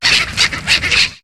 Cri de Vostourno dans Pokémon HOME.